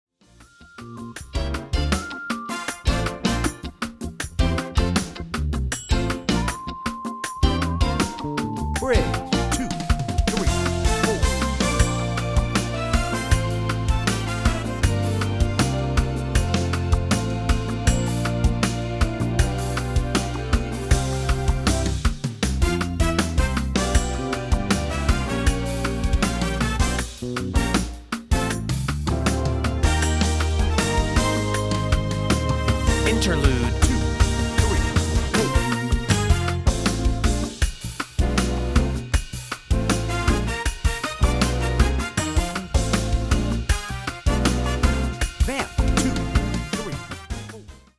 Key: Eb | Tempo: 158 BPM. https